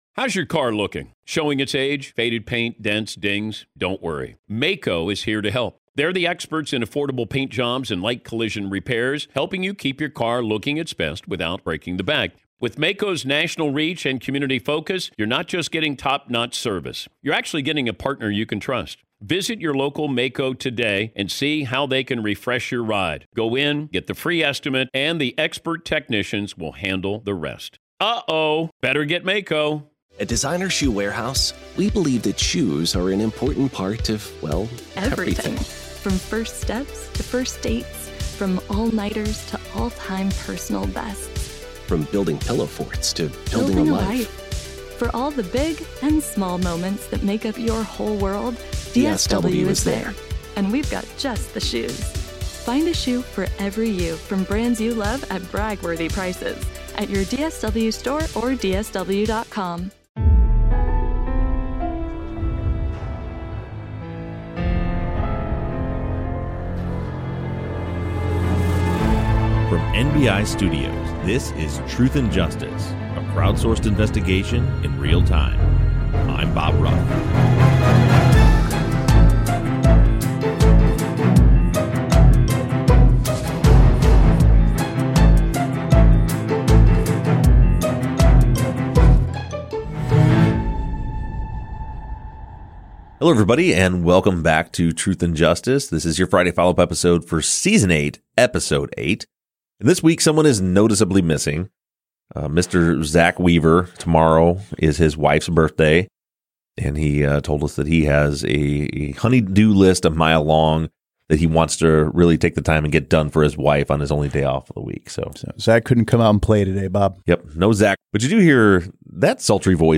sit down in the studio to discuss listener questions